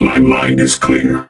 robo_bo_ulti_vo_03.ogg